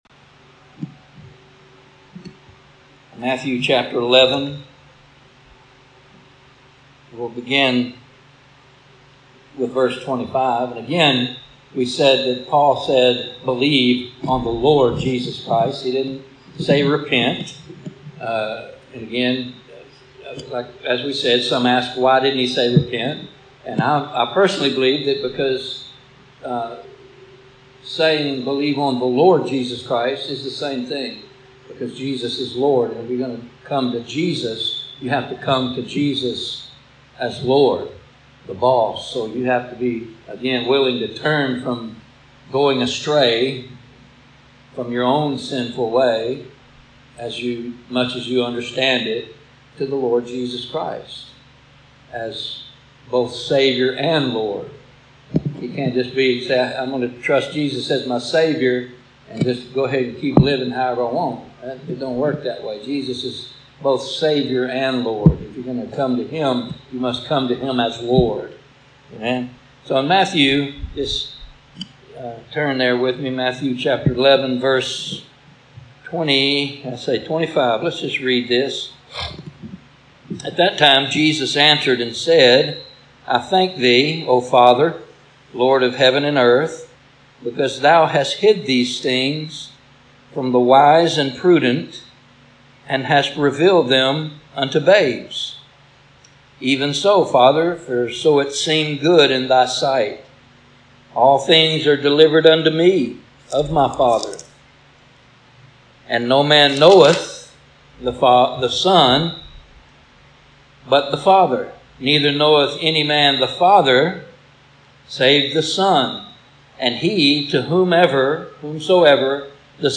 Passage: Matthew 11:25-30 Service Type: Thursday Evening « God